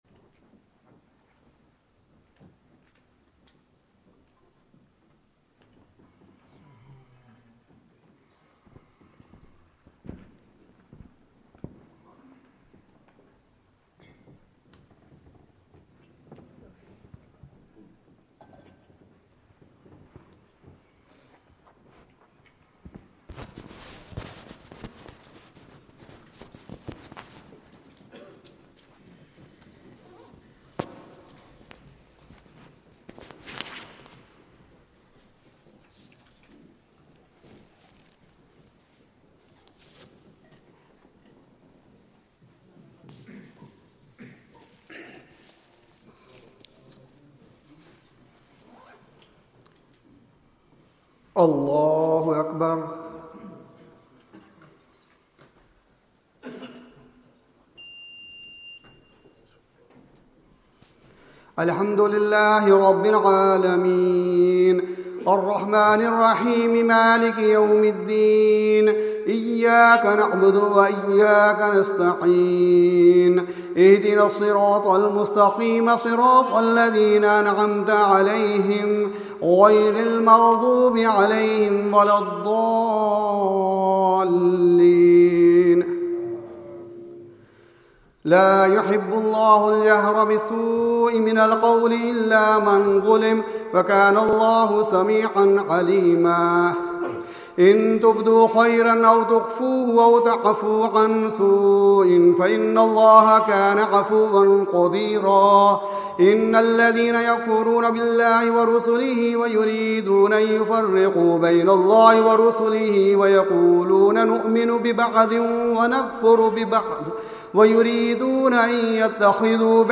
Taraweeh